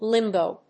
発音記号
• / límboʊ(米国英語)
• / límbəʊ(英国英語)